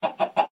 chicken_say3.ogg